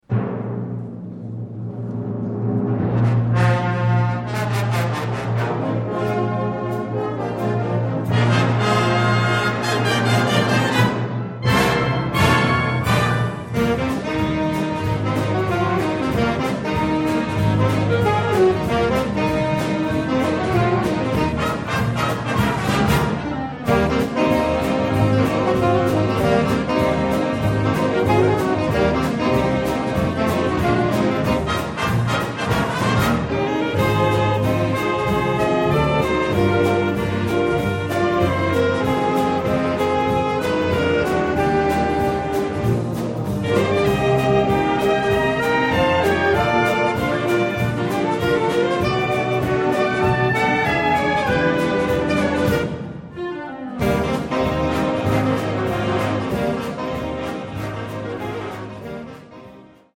4 Saxophonen & Blasorchester